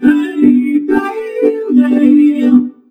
VOXVOCODE2-R.wav